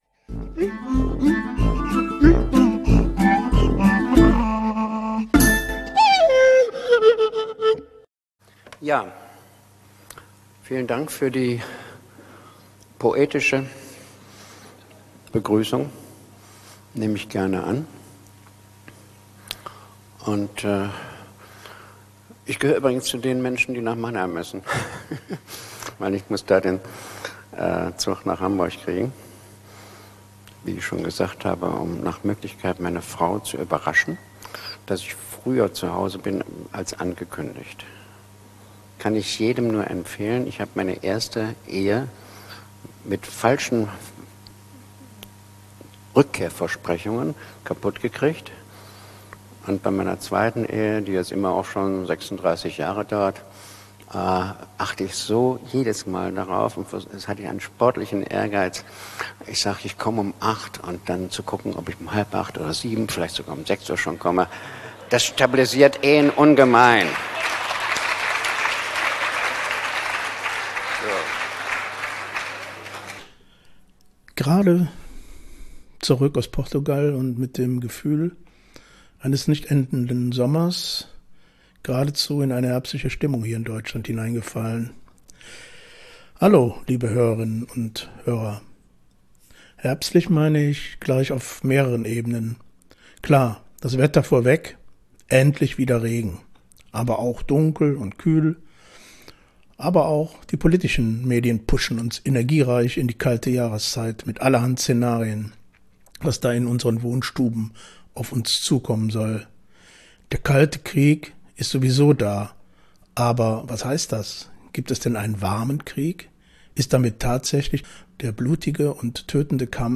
Bereichert mit Interviews von Insidern aus den verschiedensten Perspektiven. In jeder Folge wird zudem ein Song aus der Rock- und Popgeschichte gespielt und der jeweilige Künstler vorgestellt.